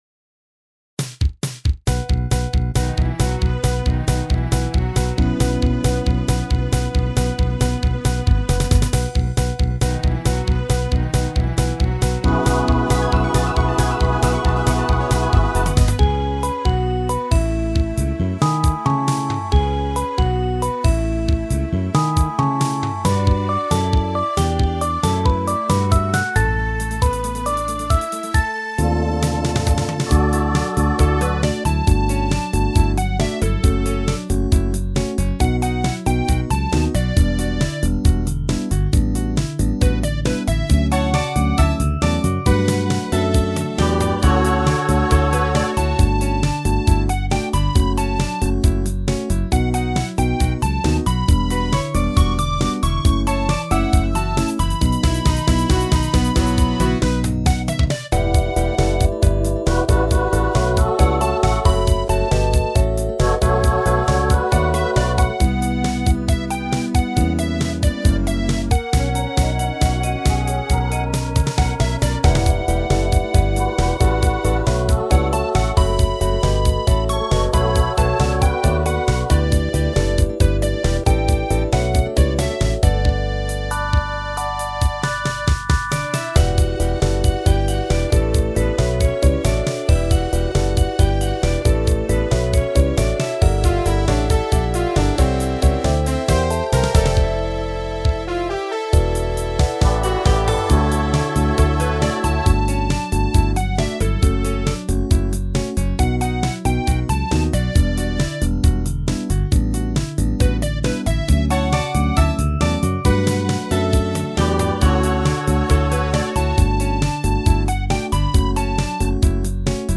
自分のMIDI作品を著作権対策と再生環境を考慮してMP3ファイルで公開しています。